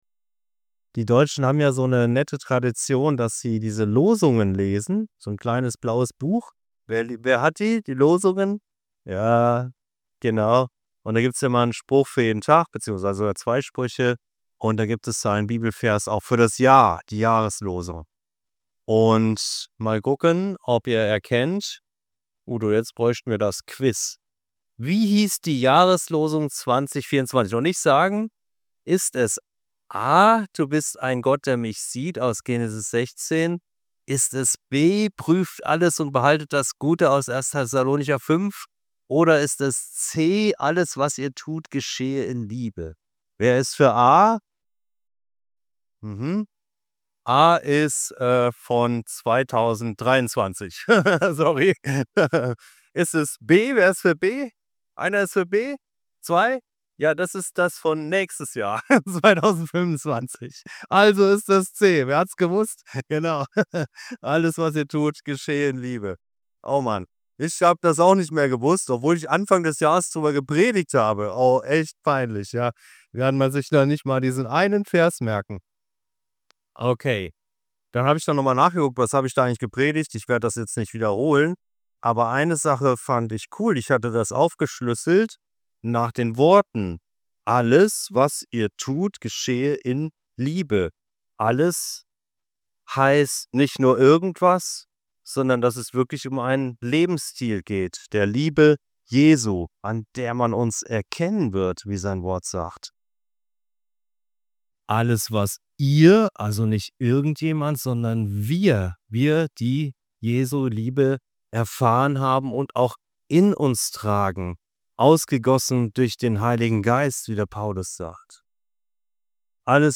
Rückblick Jahreslosung 2024 ~ Anskar-Kirche Hamburg- Predigten Podcast